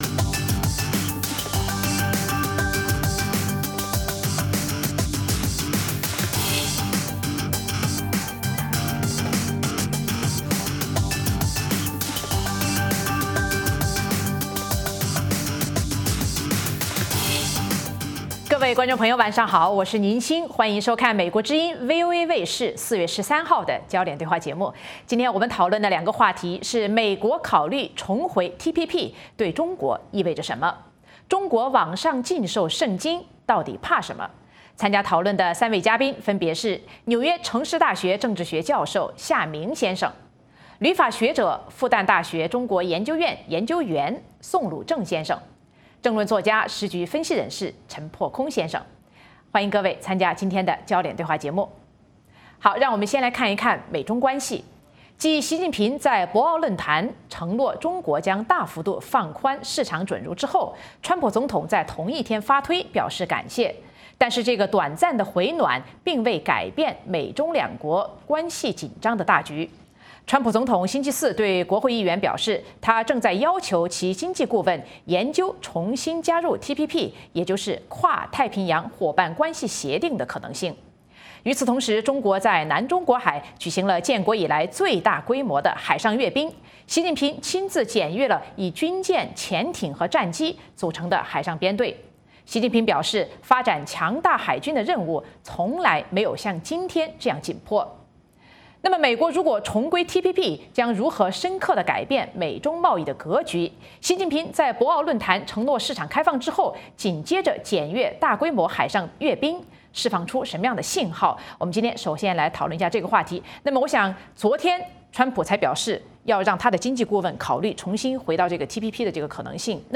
《焦点对话》节目追踪国际大事、聚焦时事热点。邀请多位嘉宾对新闻事件进行分析、解读和评论。或针锋相对、或侃侃而谈。